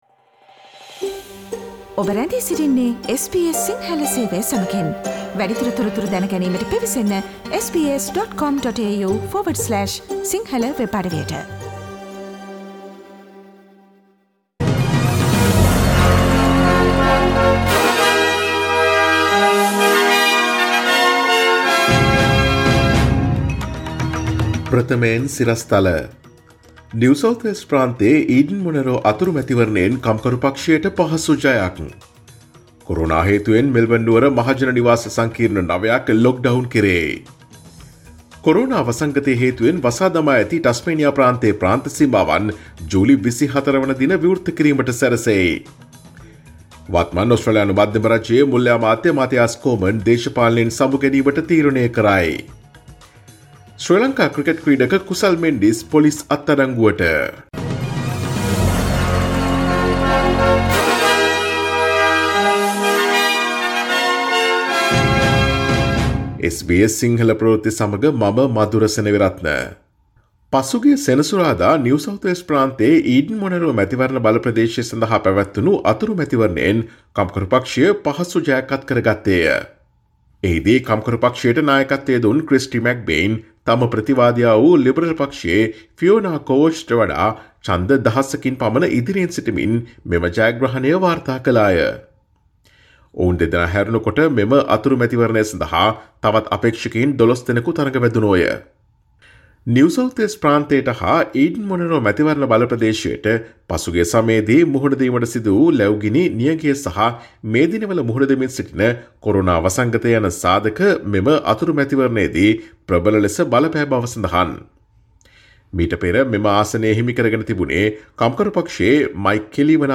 Daily News bulletin of SBS Sinhala Service: Monday 06 July 2020